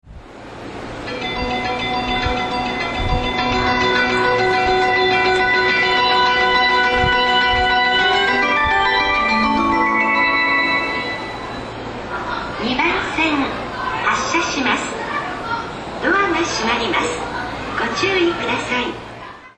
2006年４月にメロディーが変更され、音程が低いものとなりました。